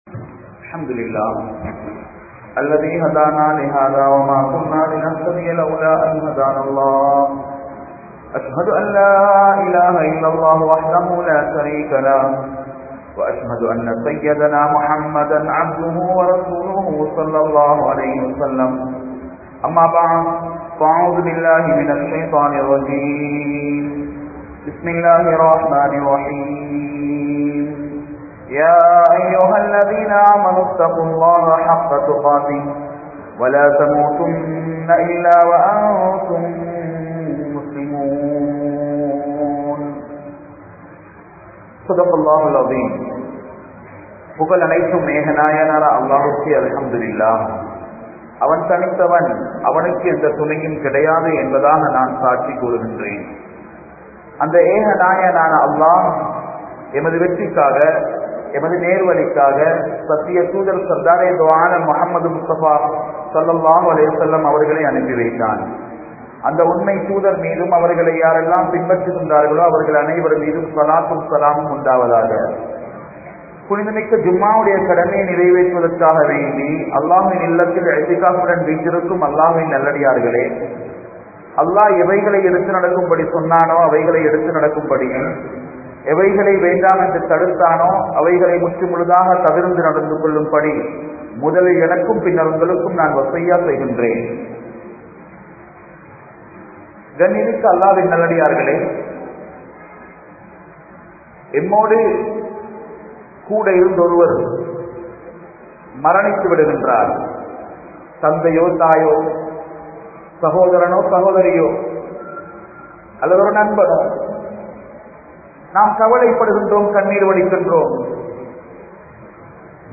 Maranaththitku Mun Maraniththu Paarpoam (மரணத்திற்கு முன் மரணித்து பார்ப்போம்) | Audio Bayans | All Ceylon Muslim Youth Community | Addalaichenai